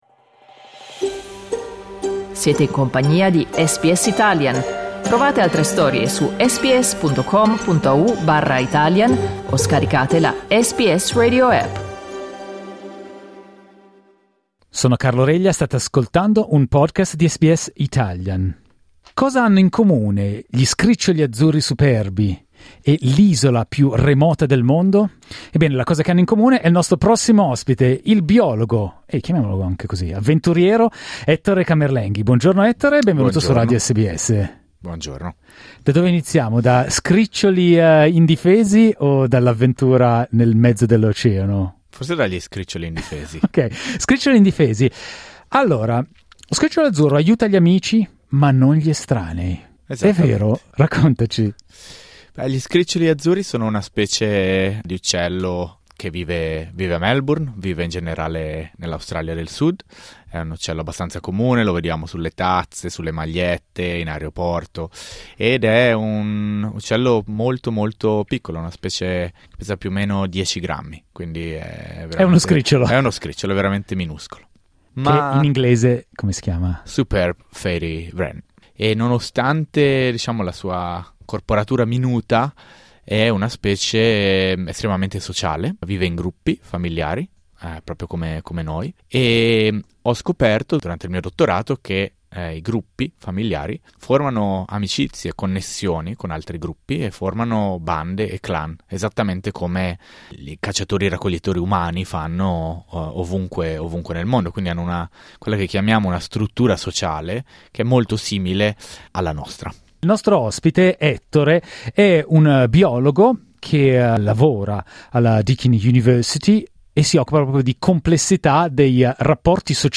In questa intervista spiega perché alcune società animali sono così complesse da risultare simili a quelle umane.